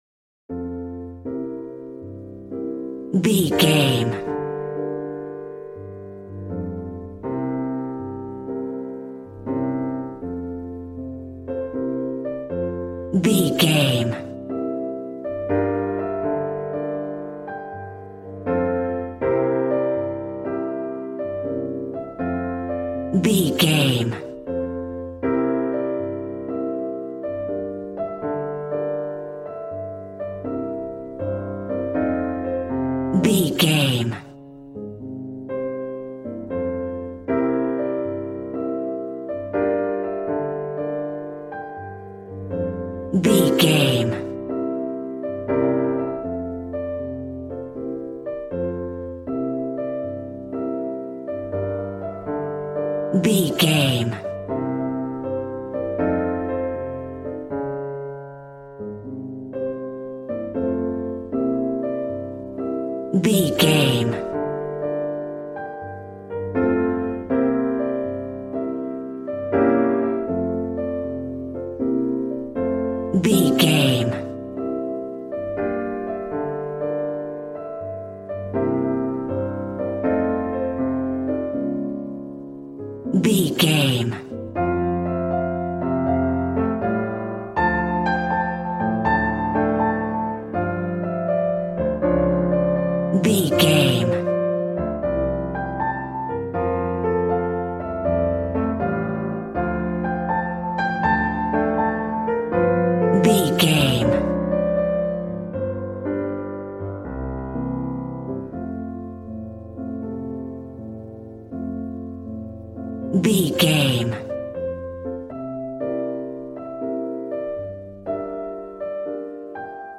Ionian/Major
piano
drums